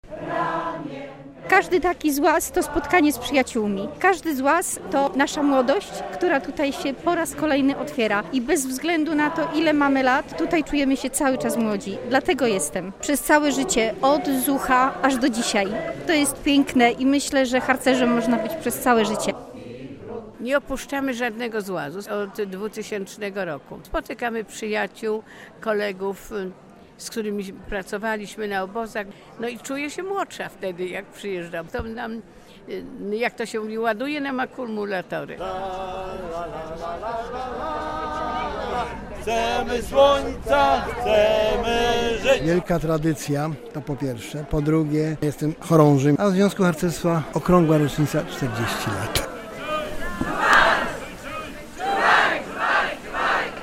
Od uroczystego apelu w Parku Branickich rozpoczął się XXXI Ogólnopolski Złaz Seniorów i Starszyzny Harcerskiej ZHP.